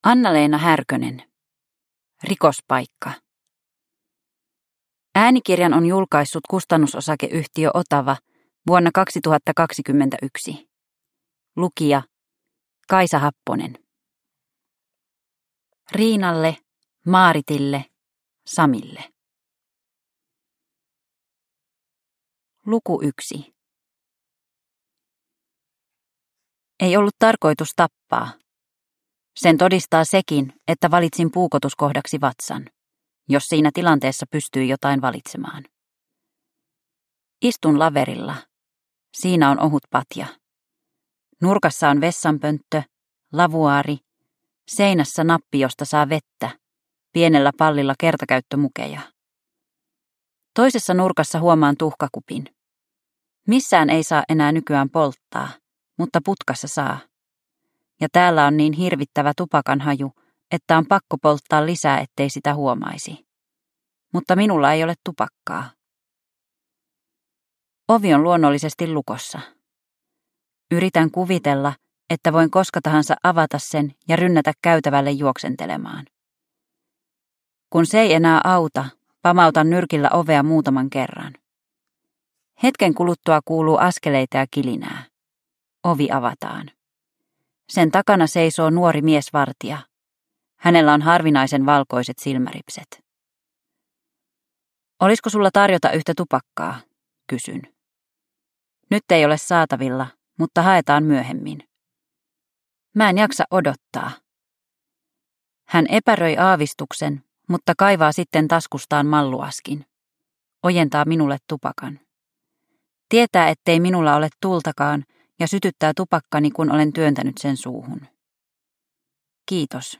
Rikospaikka – Ljudbok